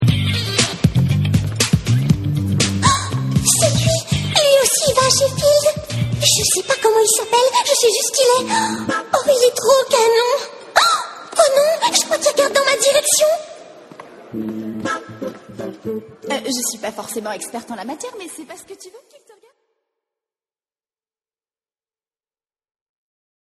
Voix jeune, fraîche, au timbre particulier...
Sprechprobe: Industrie (Muttersprache):
Very often work as a teenage voice, or child (girls or boys), and young adults...